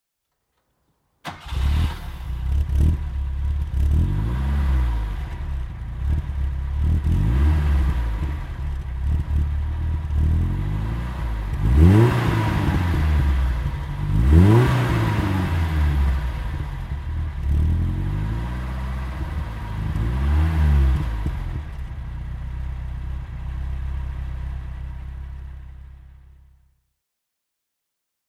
BMW 316 TC1 Baur Cabriolet (1978) - Starten und Leerlauf